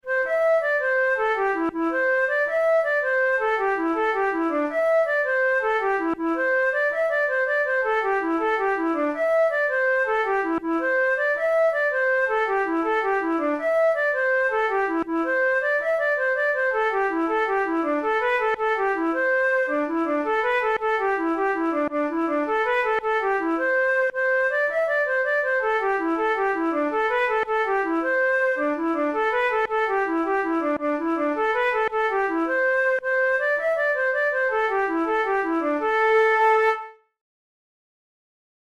InstrumentationFlute solo
KeyA minor
Time signature6/8
Tempo108 BPM
Traditional Irish jig